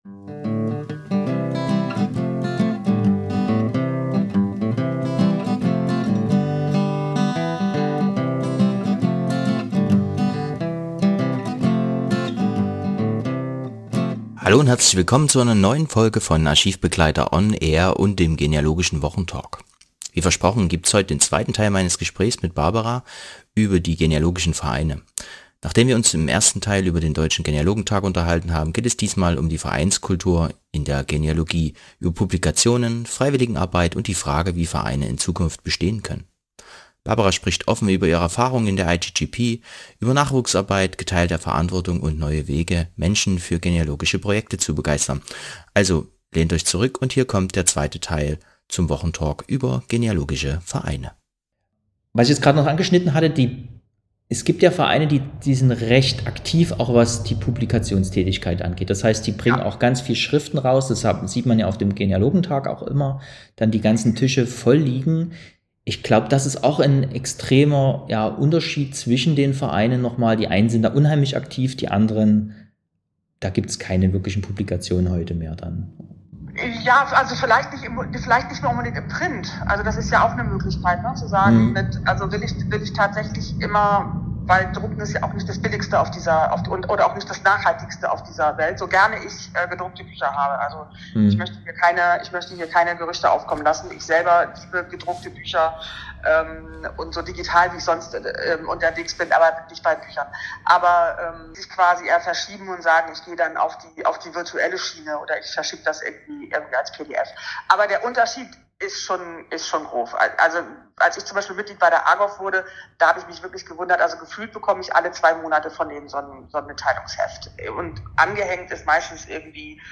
Außerdem geht es um internationale Zusammenarbeit und darum, wie sich Gemeinschaft auch jenseits klassischer Vereinsstrukturen gestalten lässt. Diese Episode ist Teil 2 des Gesprächs, denn beim Thema Vereine gibt es so viel zu sagen.